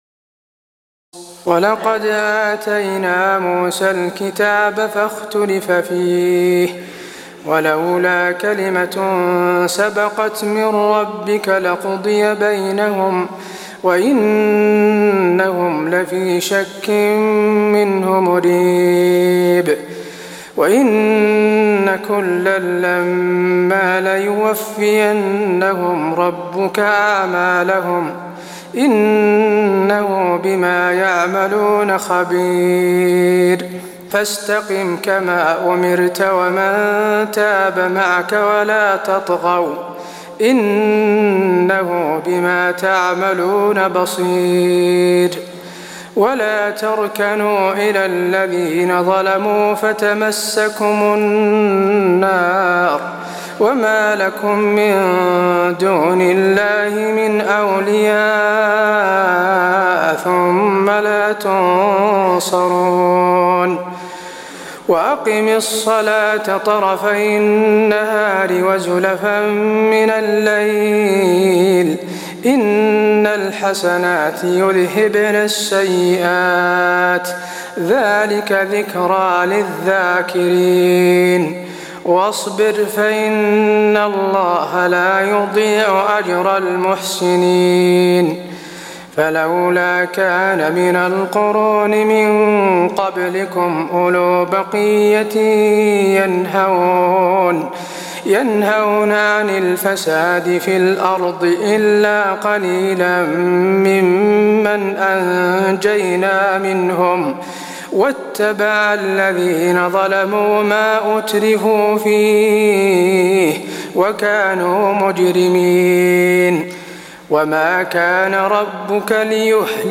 تراويح الليلة الحادية عشر رمضان 1423هـ من سورتي هود (110-123) و يوسف (1-29) Taraweeh 11 st night Ramadan 1423H from Surah Hud and Yusuf > تراويح الحرم النبوي عام 1423 🕌 > التراويح - تلاوات الحرمين